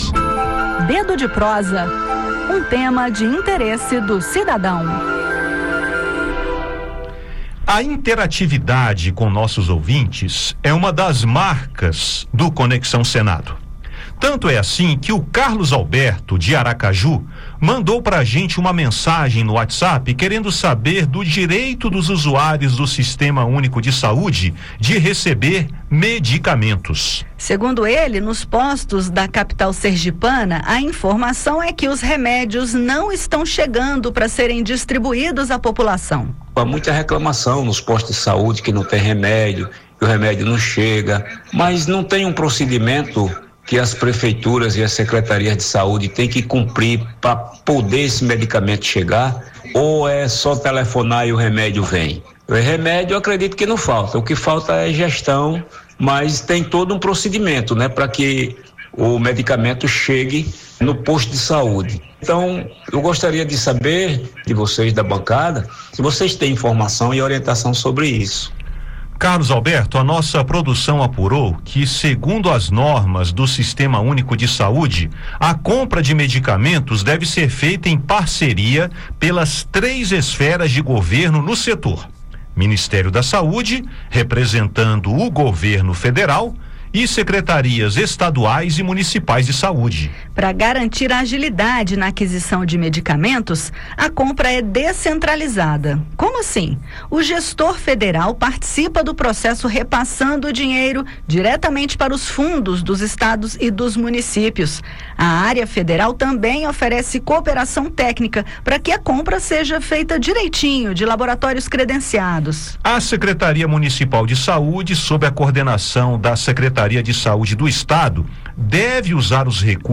Dedo de Prosa desta quinta-feira (15) fala sobre os direitos dos cidadãos em relação aos medicamentos gratuitos distribuídos pelo Sistema Único de Saúde (SUS). No bate-papo, você vai entender como é feita a aquisição e a distribuição, que envolve parceria das três esferas de governo: Ministério da Saúde, representando o governo federal, e secretarias estaduais e municipais de saúde. Também vai saber o que fazer se faltar medicamentos no SUS de sua cidade, em especial os de alto custo, e como enviar suas dúvidas aos canais oficiais.